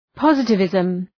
Προφορά
{‘pɒzıtı,vızm}